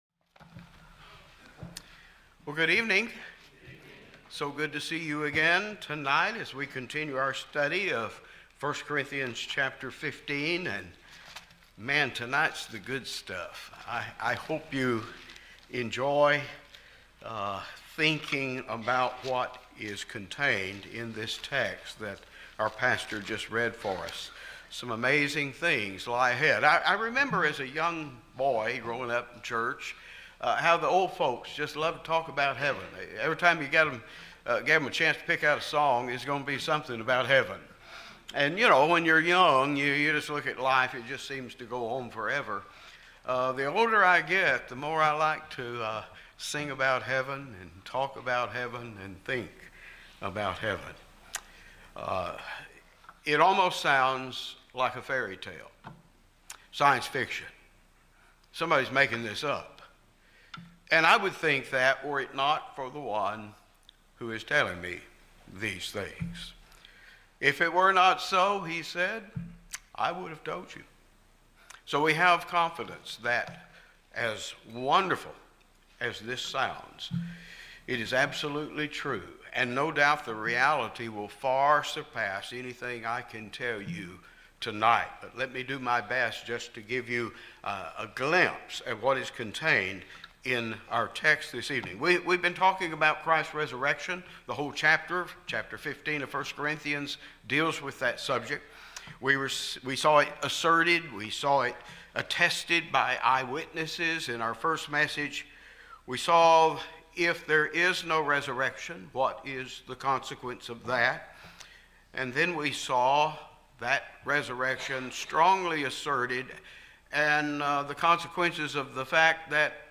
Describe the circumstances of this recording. This is the fourth of five messages in the 2026 Spring Bible Conference